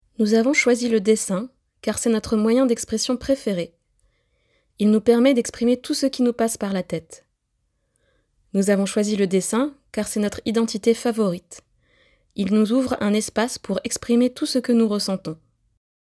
Et pour terminer leur réalisation, les résidents participants ont enregistré des témoignages en lien avec leur portrait ou bien leur nature morte, en français mais également dans leur langue maternelle pour ceux issus d’autres pays (témoignages en ukrainien, en albanais ou en arabe).